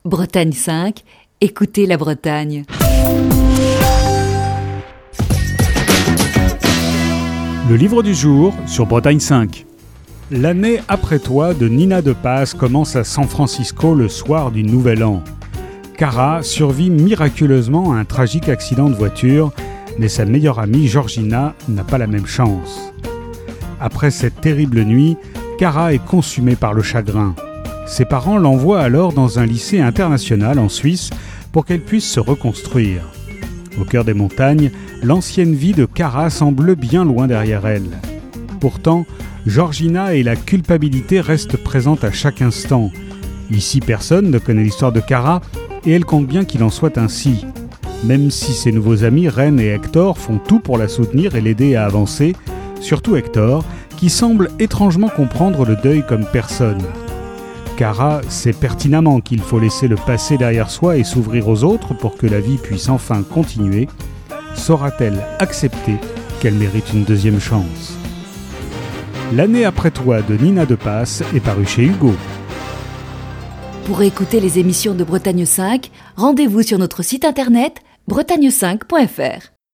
Chronique du 4 février 2020.